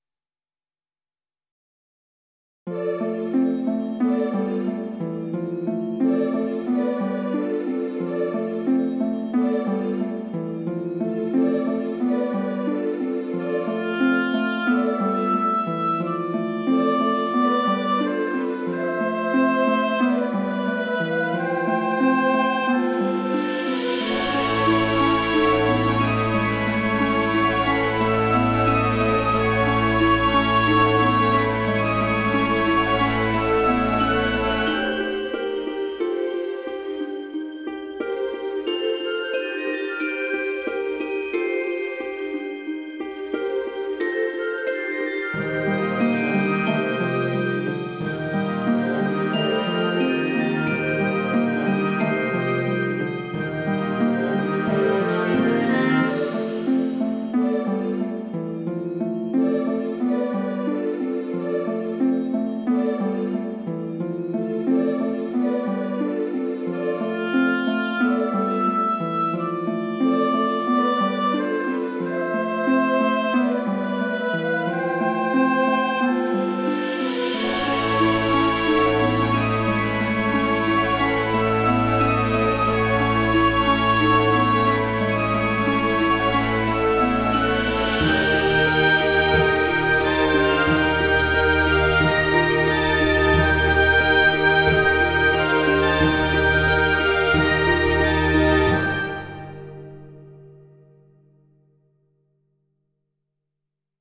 Ambiance religieuse, sereine et feuillue, hautbois limpide,
eclat sublime et noble, sans trop de lourdeur,
puis recoin de fraicheur avec flute légère comme l'air,
fin un peu soudaine pour une telle ambiance !
Dans ta compo, je ressens vraiment la fraîcheur, la lumière, la richesse d'un jardin.
Emotion : Rhaa ! des scintillements, des surenchères brillantes, des murmures pudiques, une voix maternelle, et ... une fin à l'arrache !
Orchestration : Limpide, sobre, equilibrée et construit par différentes phases. Mais un peu court.
Son : Yo, ample et clair, sans fouilli